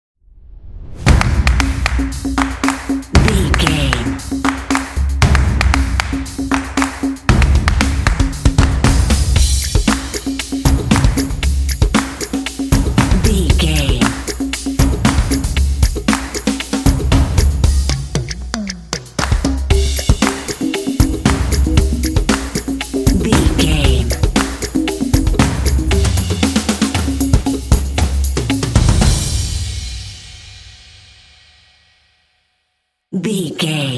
Atonal
driving
motivational
drumline